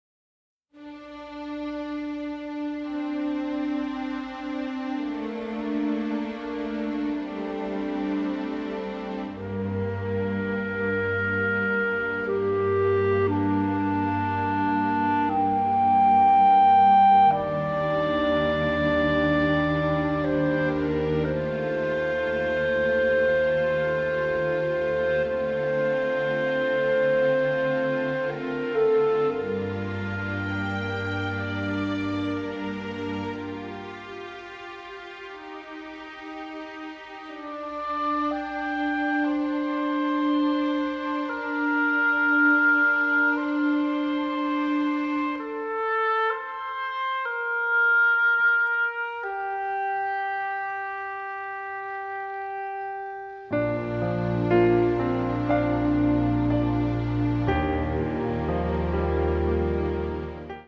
a bubbly score featuring lush orchestrations